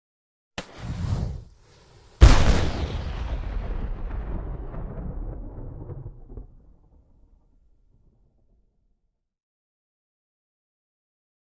Large Single Cannon Fire with Fuse; Bright Flare Up As Fuse Is Lit Followed By A Huge Cannon Blast And Long Echo. Close Up Perspective.